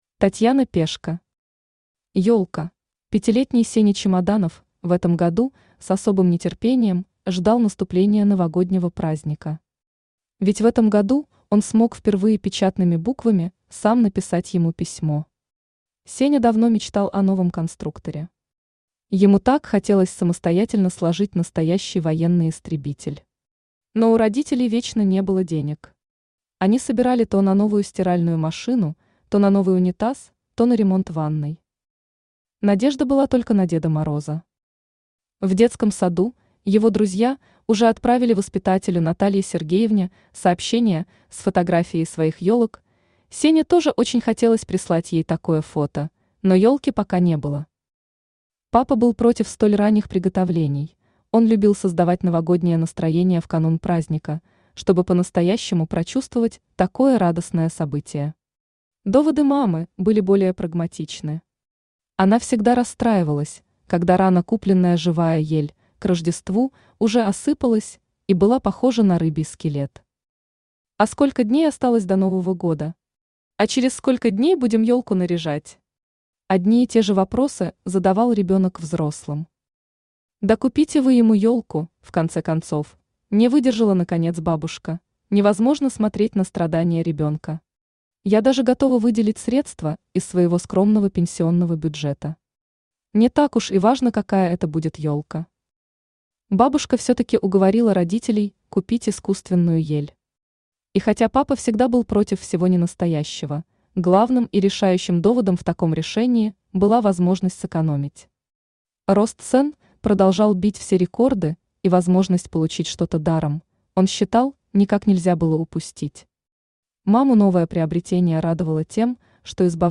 Аудиокнига Ёлка | Библиотека аудиокниг
Aудиокнига Ёлка Автор Татьяна Пешко Читает аудиокнигу Авточтец ЛитРес.